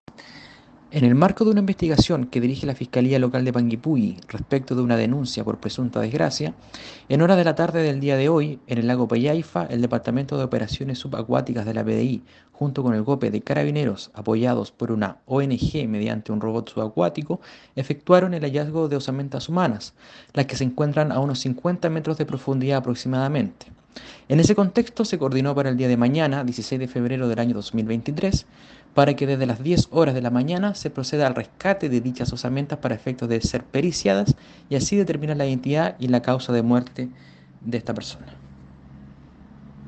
Fiscal de Panguipulli Eduardo Díaz.